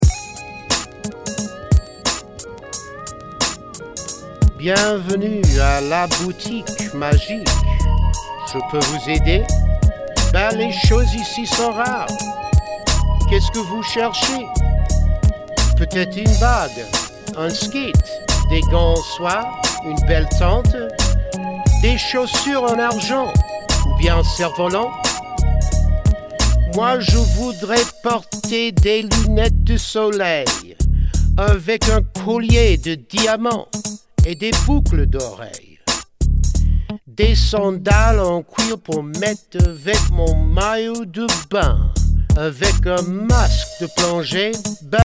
French Language Raps
Ten original raps on CD.